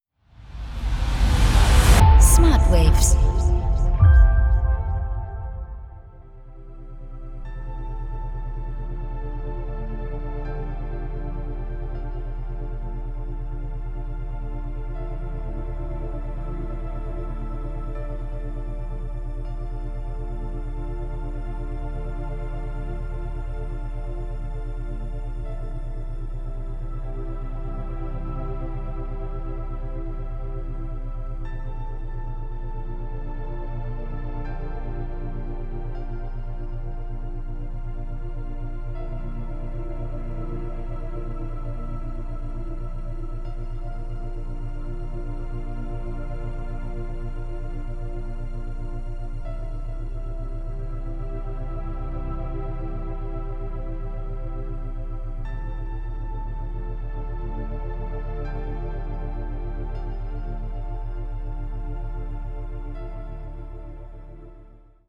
Binaurale & Isochrone Beats